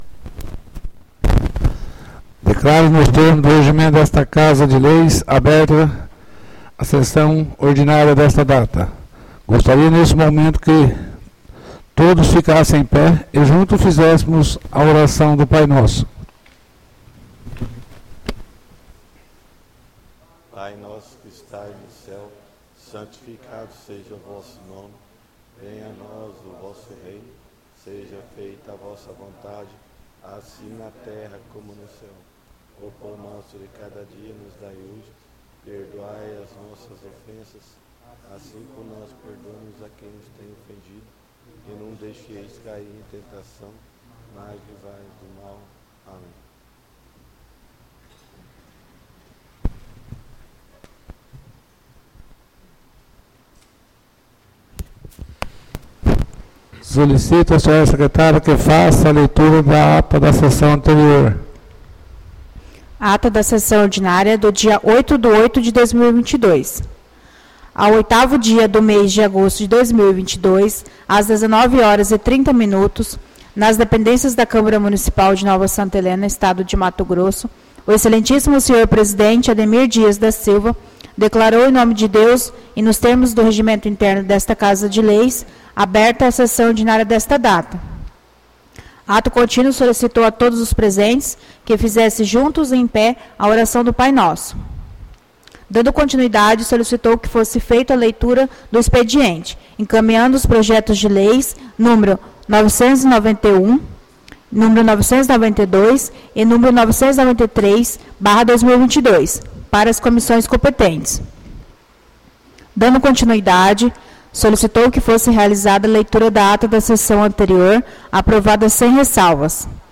ÁUDIO SESSÃO 15-08-22 — CÂMARA MUNICIPAL DE NOVA SANTA HELENA - MT